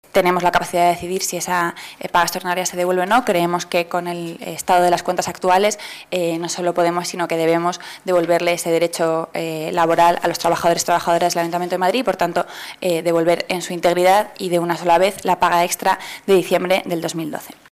Nueva ventana:Rita Maestre, portavoz del Gobierno municipal